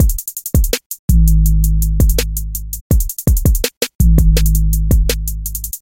邻居的音乐鼓循环
描述：鼓循环165bpm
Tag: 165 bpm Rap Loops Drum Loops 1 002.45 KB wav Key : Unknown